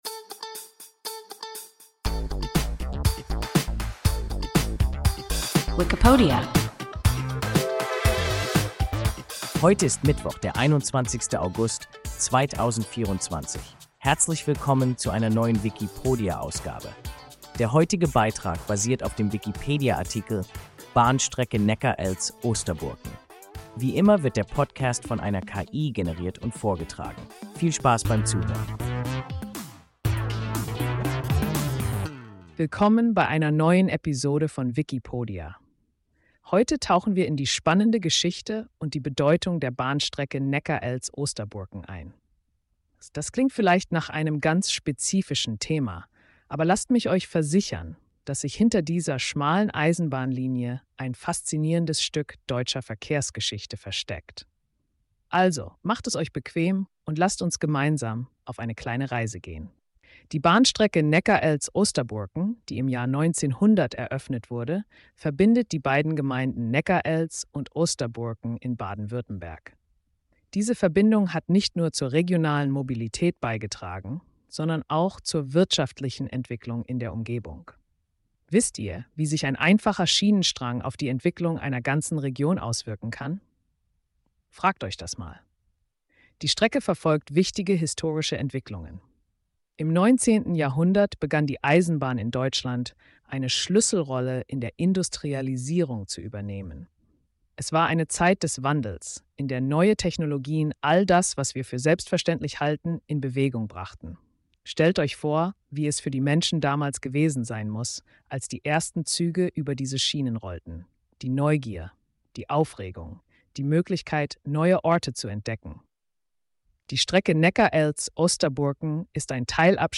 Bahnstrecke Neckarelz–Osterburken – WIKIPODIA – ein KI Podcast